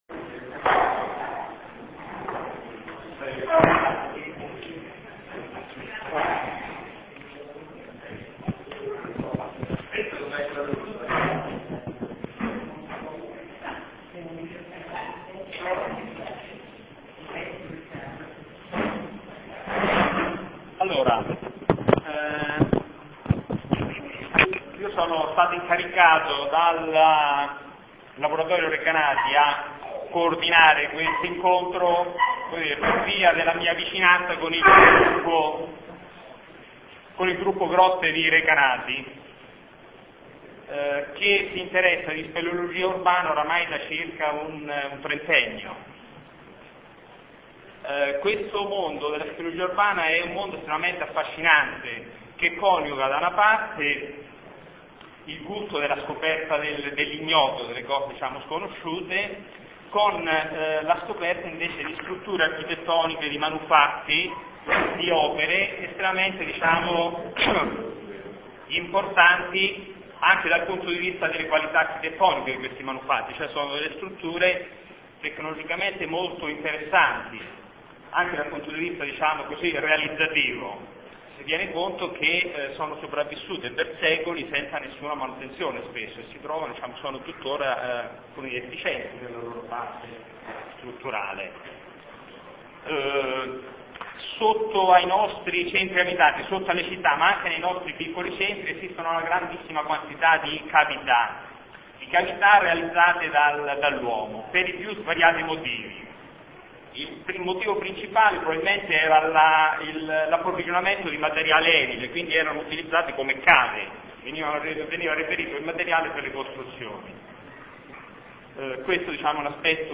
Ascolta la registrazione audio di un incontro pubblico sul tema